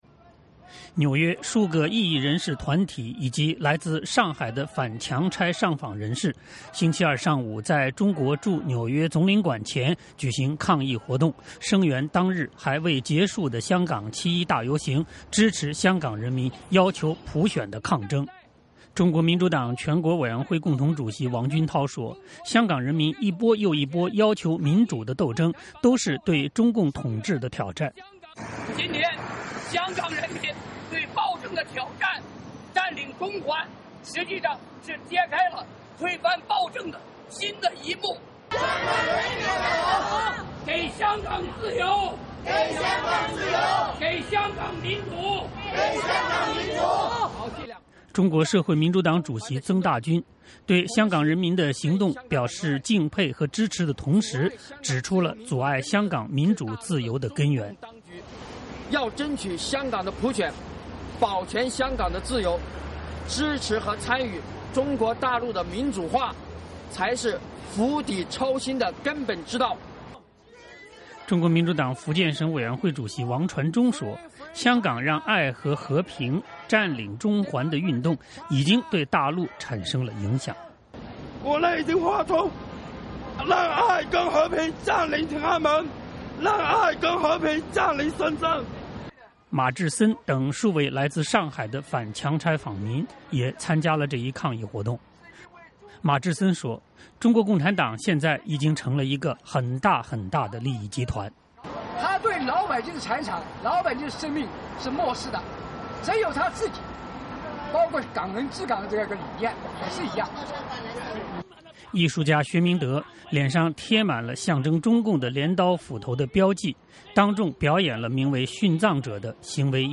抗议者高呼口号：“声援香港人民！声援占中！给香港自由！给香港民主！”